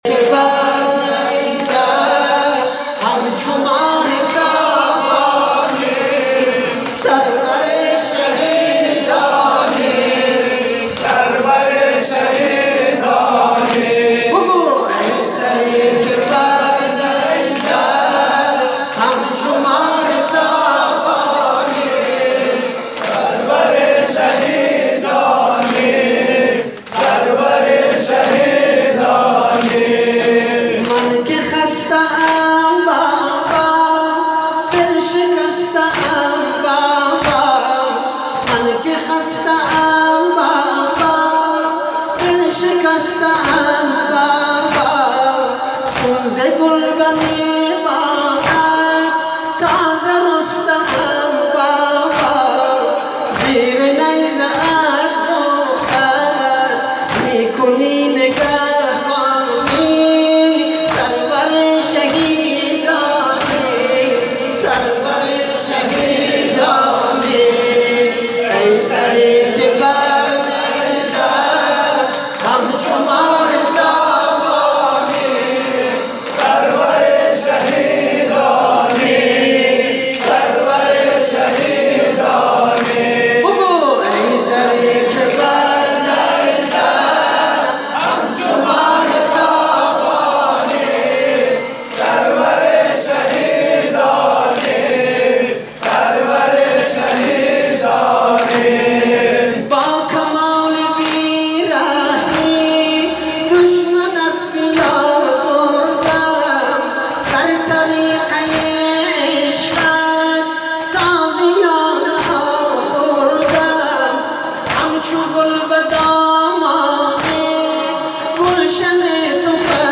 برچسب ها: شیعیان افغانستان نوحه محرم 1403 مشهد